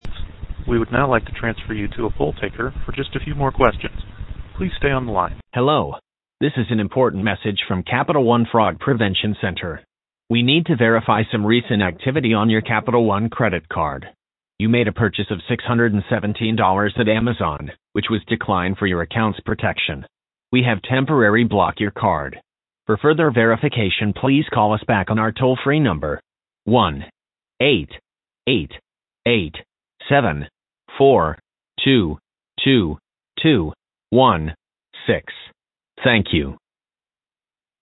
Robocall :arrow_down: